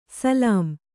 ♪ salām